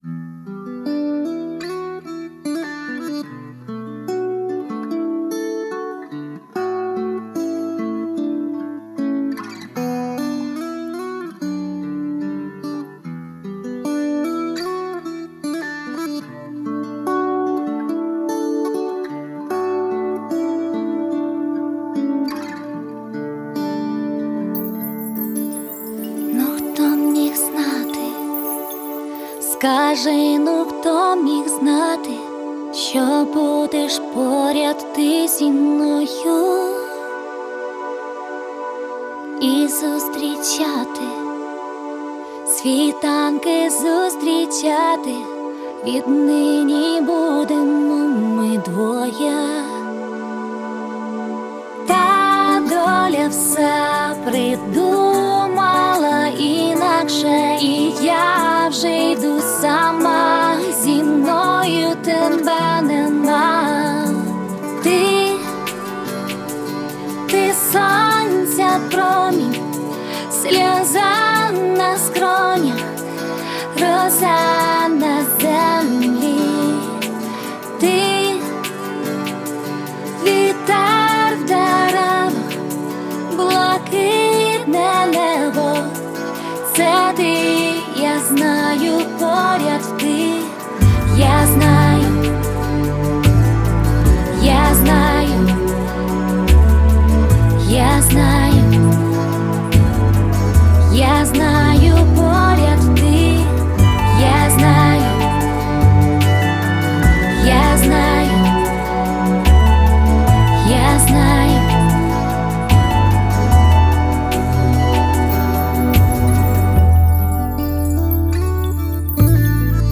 Жіноча
Мецо-сопрано